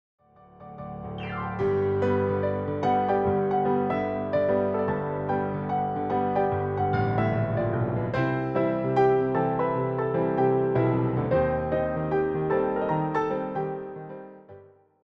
The performance favors clean voicing and balanced dynamics